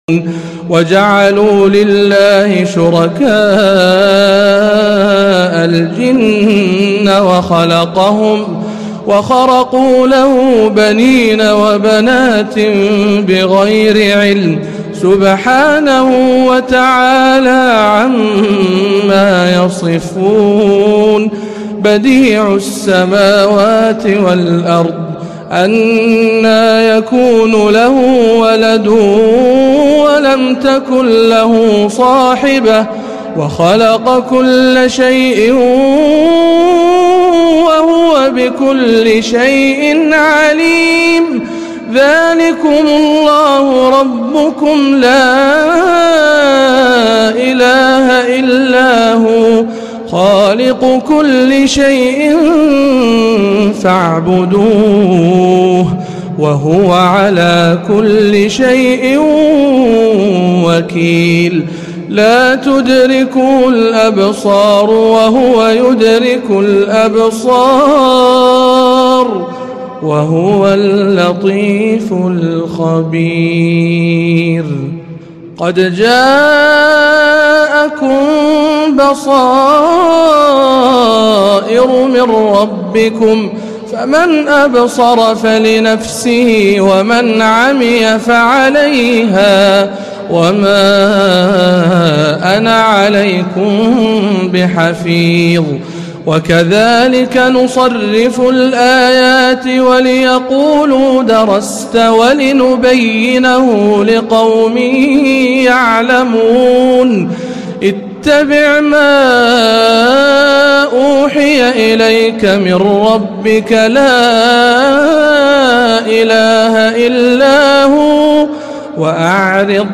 تلاوة مميزة من سورة الأنعام